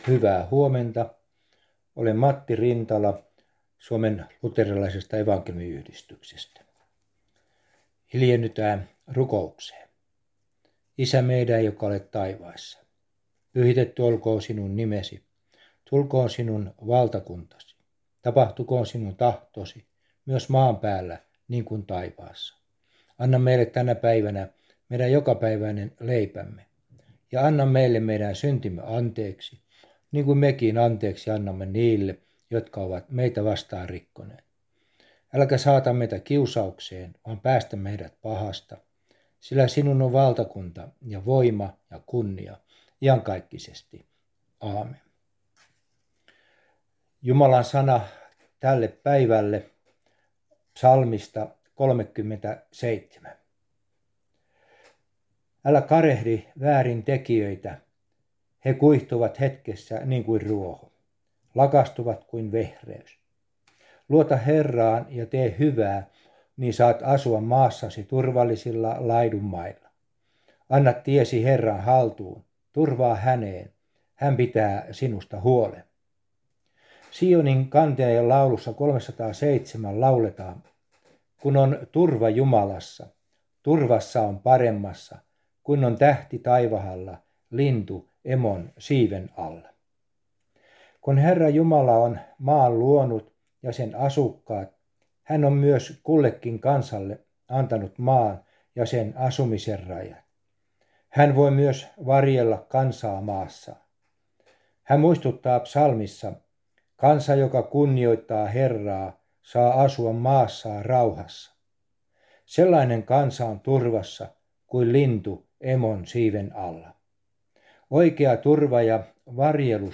Aamuhartaus